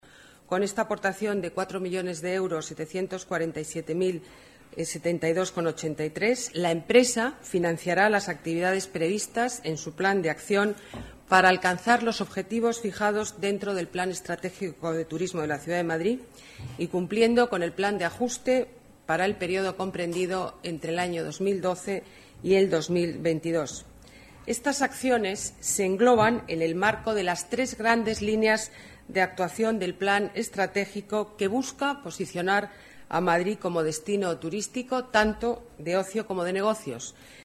Nueva ventana:Declaraciones alcaldesa de Madrid, Ana Botella: